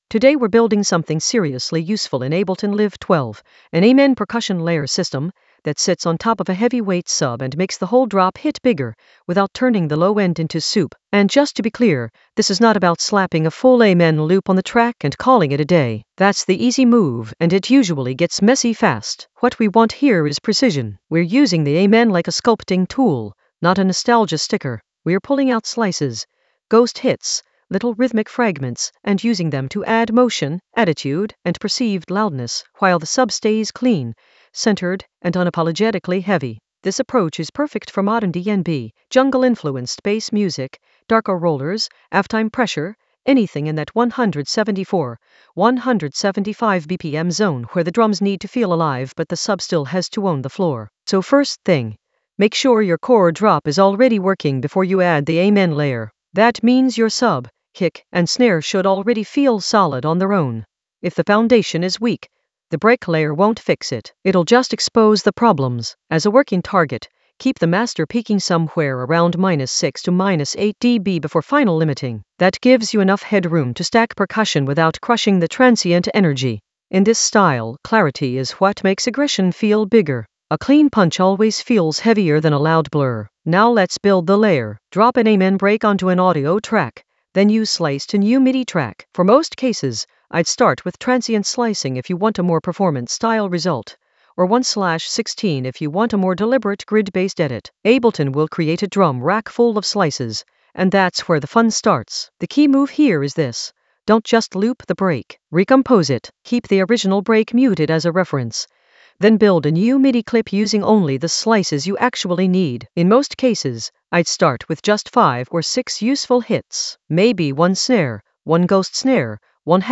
Narrated lesson audio
The voice track includes the tutorial plus extra teacher commentary.
An AI-generated advanced Ableton lesson focused on Amen Science Ableton Live 12 percussion layer masterclass for heavyweight sub impact in the Sampling area of drum and bass production.